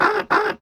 Crow
Crow sound made with my own voice and some EQ effects.
crow_0.ogg